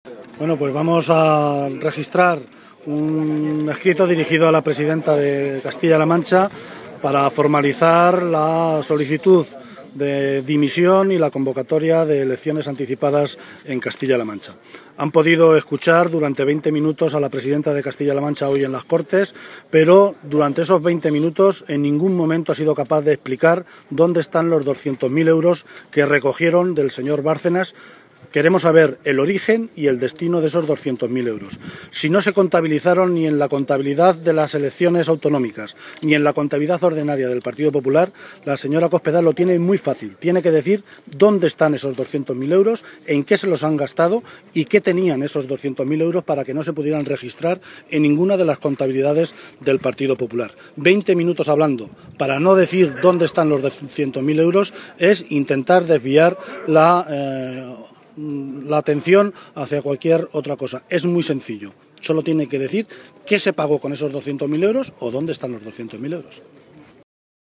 Tras la presentación del escrito ante el Registro, el portavoz socialista criticó la intervención de Cospedal ante el pleno ya que “no ha explicado ni donde están ni cuál es el origen de esos 200.000 euros, lo demás es intentar desviar la atención de lo que realmente quieren saber los ciudadanos”.
Declaraciones Martínez Guijarro_Registro Cortes C-LM_230713